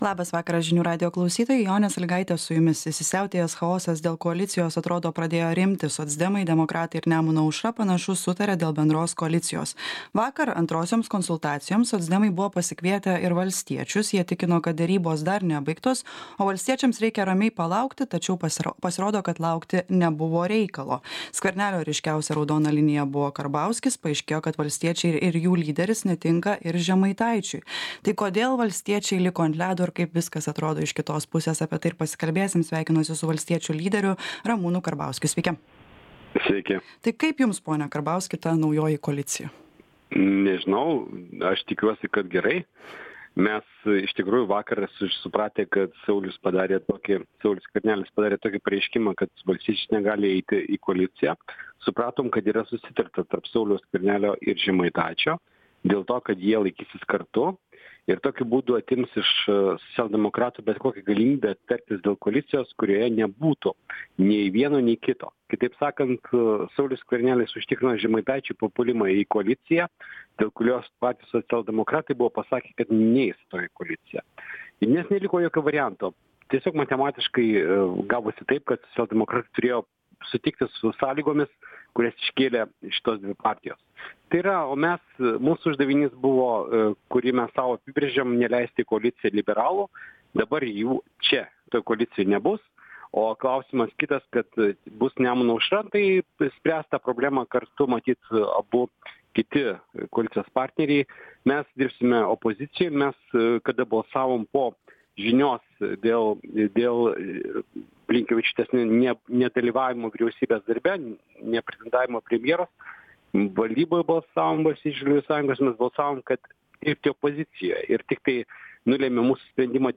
Pokalbis su „valstiečių" lyderiu R. Karbauskiu.